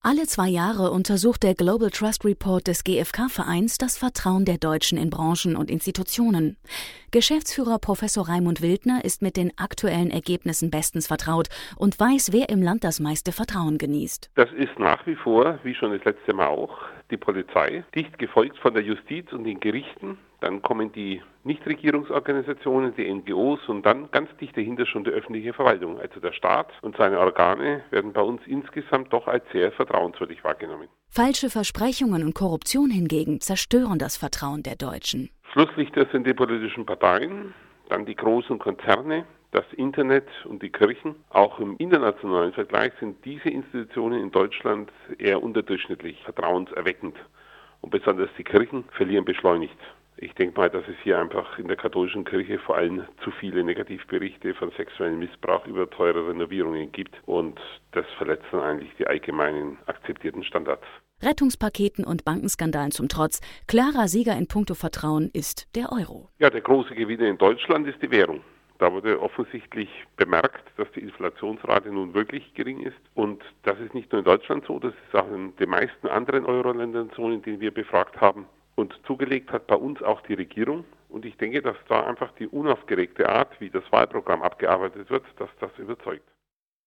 Hörfunkinterview